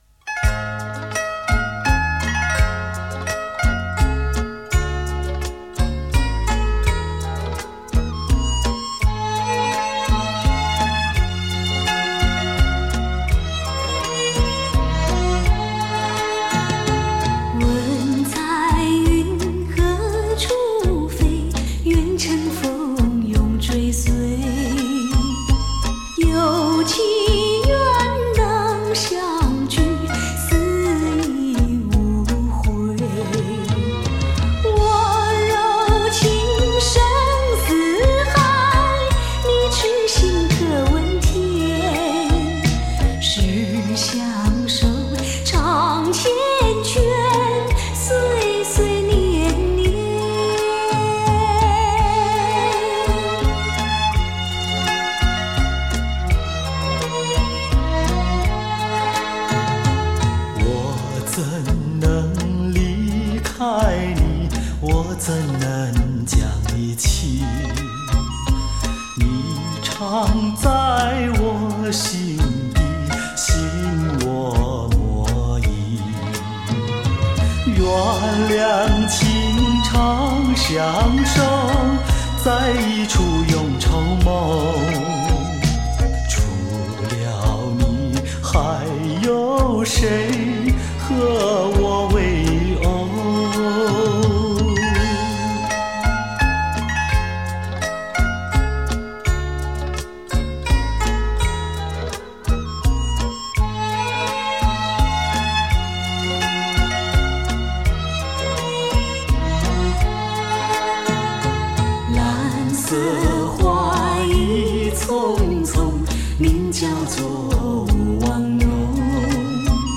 情歌对唱
香醇·清新·典雅·浪漫